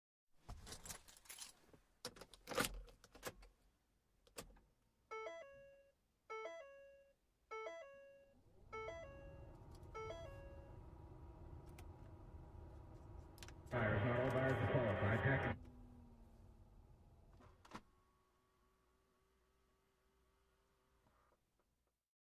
Getting-Into-Car-with-Radio-Sound-Effect-for-editing.mp3